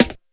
41 SD 01  -L.wav